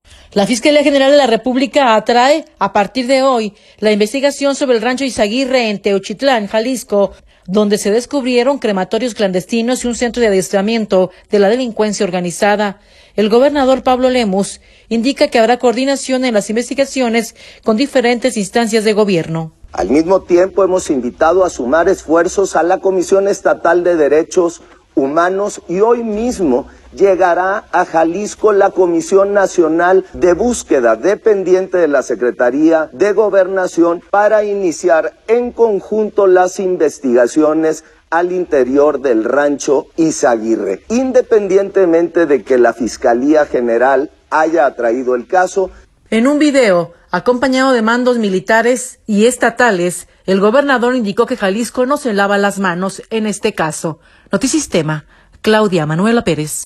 En un video, acompañado de mandos militares y estatales, el Gobernador indicó que Jalisco no se lava las manos en este caso.